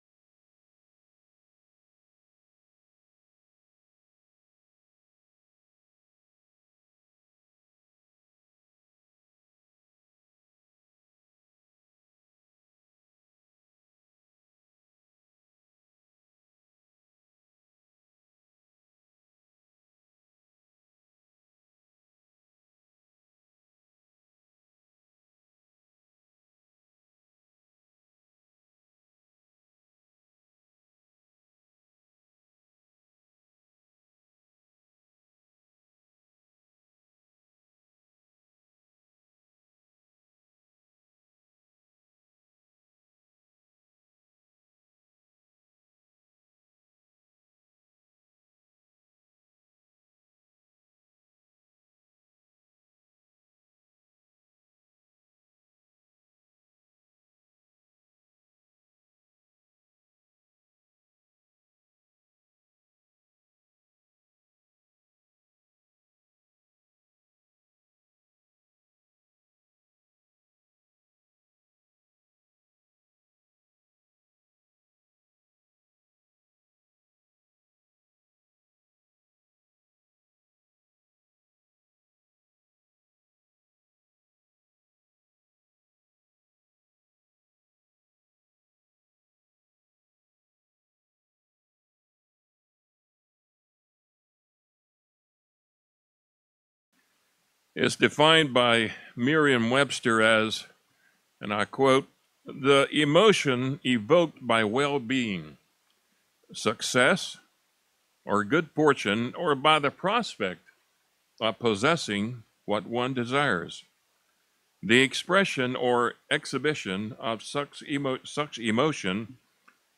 Godly Joy gives us the ability to have joy even in the face of adversity. In this sermon we'll examine real "Biblical Joy" and the benefit it provides to those who possess it.
Given in Tampa, FL